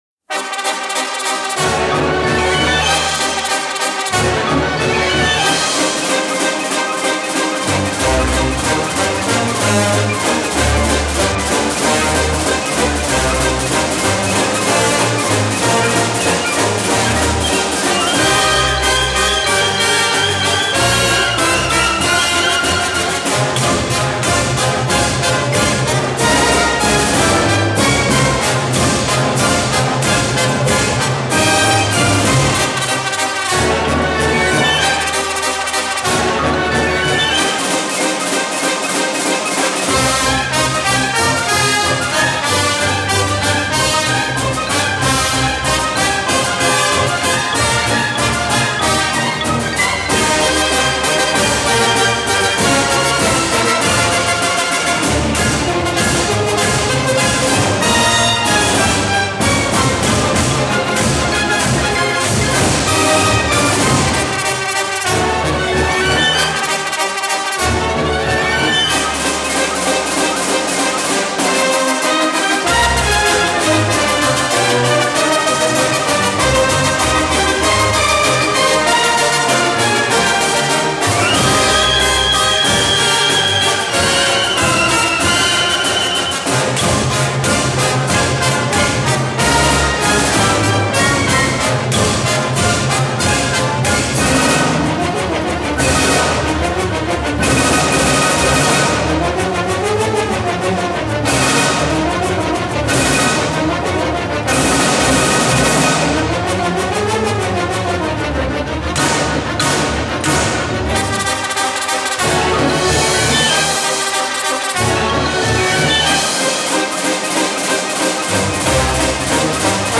инстр. версия